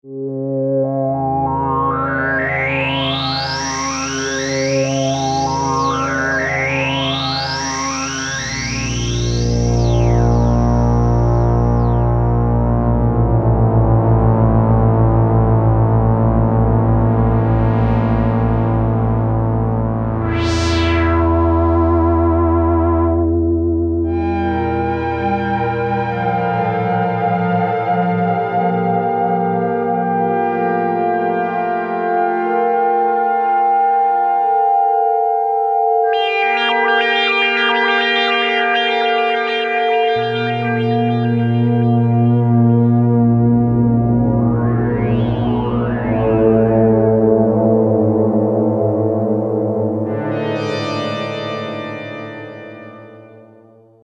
A signature bank from UltraRare containing 32 deep-sounding presets recorded from the original Tiracon 6v synthesizer.
Tiracon Deep Strings